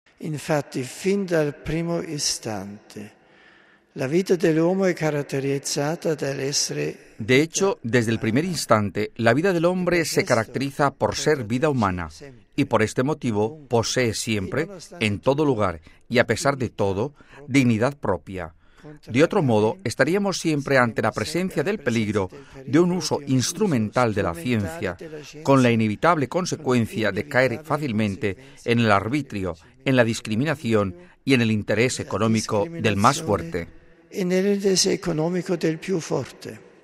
Sábado, 13 feb (RV).- En ocasión de la Asamblea general de la Pontificia Academia para la Vida, que reflexiona sobre la relación entre bioética y ley moral natural, Benedicto XVI ha recordado en un discurso pronunciado esta mañana ante los miembros de esta Academia, que la vida del hombre tiene dignidad propia.